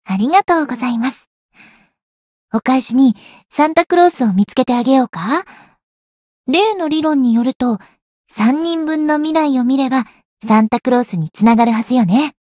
灵魂潮汐-星见亚砂-圣诞节（送礼语音）.ogg